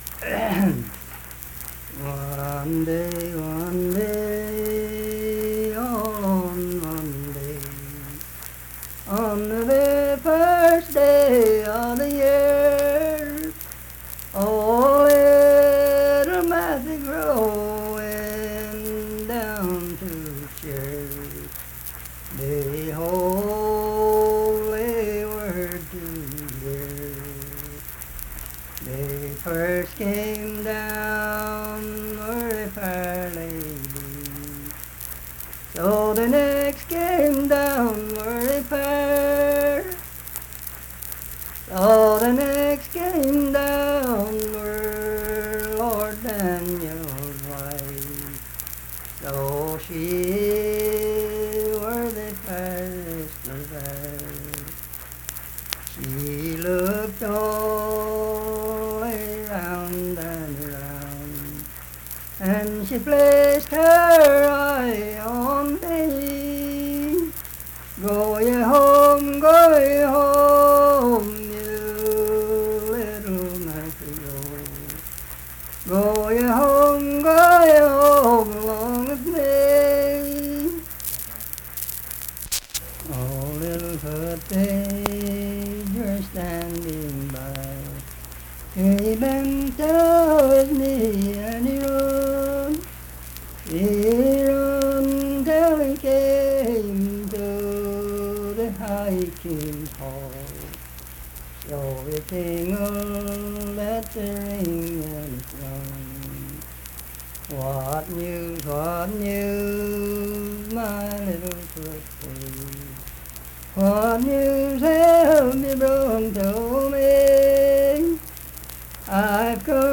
Unaccompanied vocal music
Verse-refrain, 18(4).
Voice (sung)